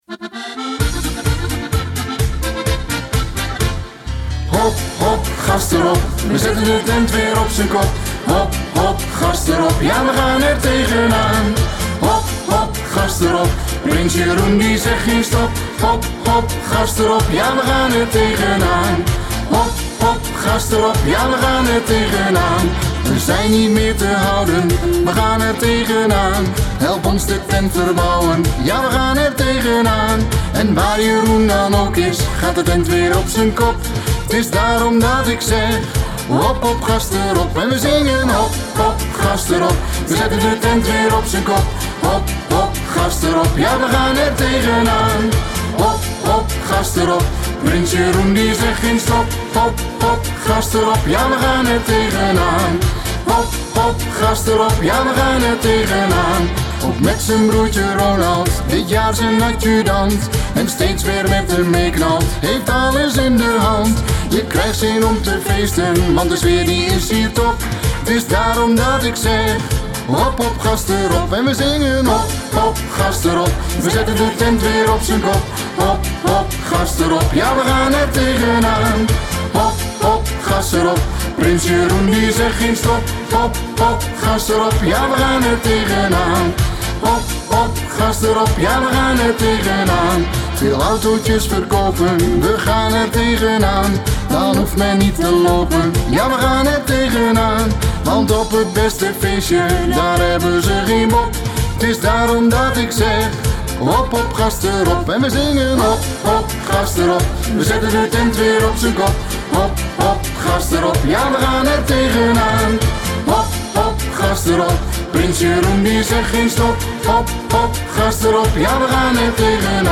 Schlager-2026.mp3